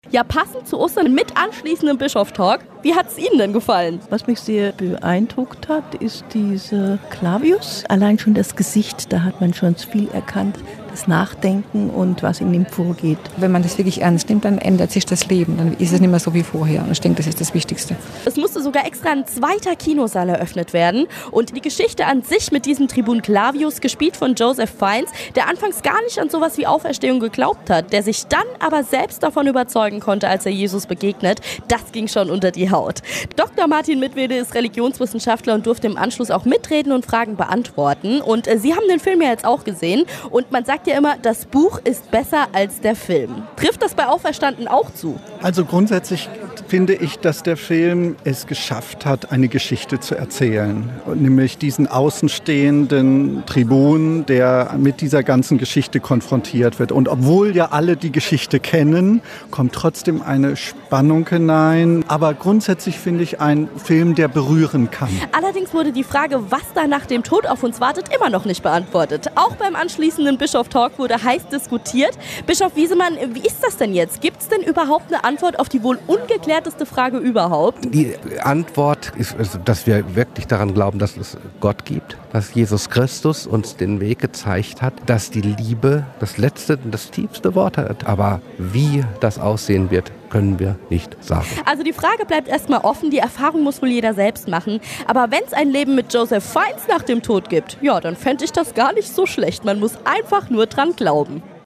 300 Beteiligte bei Filmtalk mit Bischof Wiesemann
Zum Hören (Beitrag von RPR1.)
Auferstanden_Kino-Talk.mp3